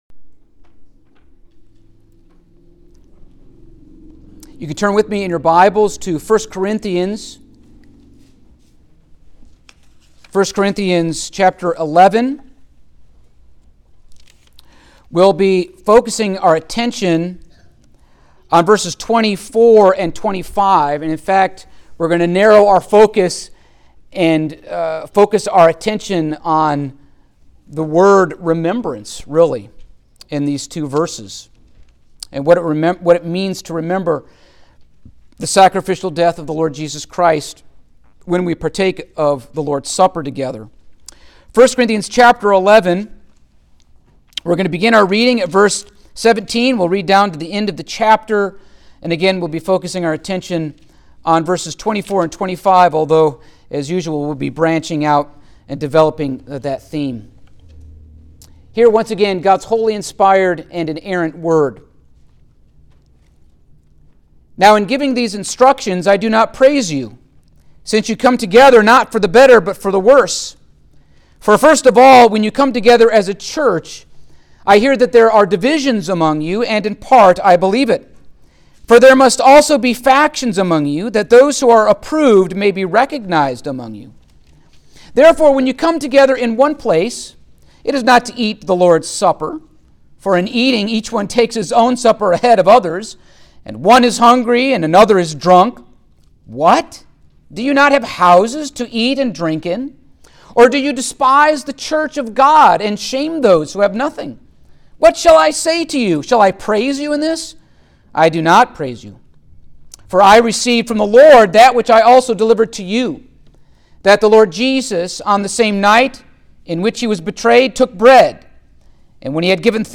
Passage: 1 Corinthians 11:24-25 Service Type: Sunday Morning